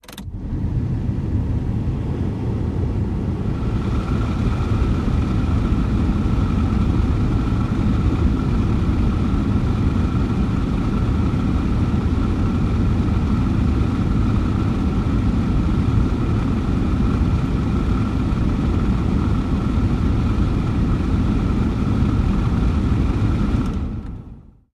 GMC Jimmy interior point of view as A/C runs at low and high speeds. Air Conditioner